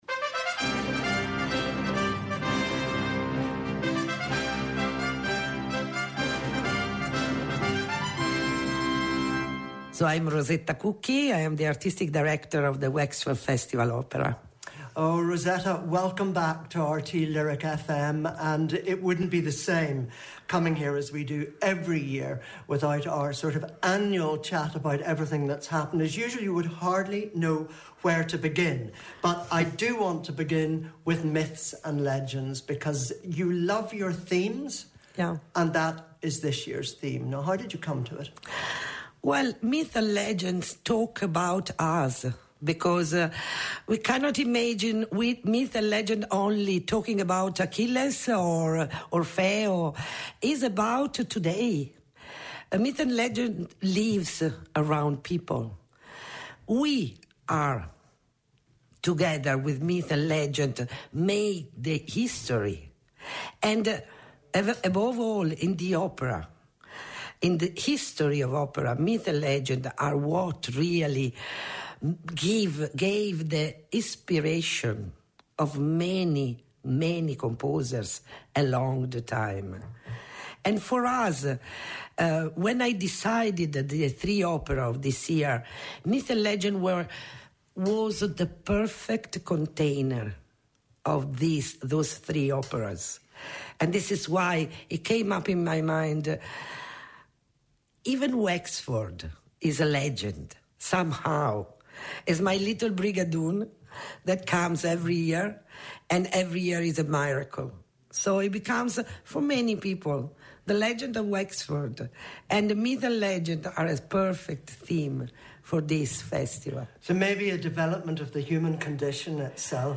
catches up with some of the cast and the creative team behind tonight's opera Le Trouvère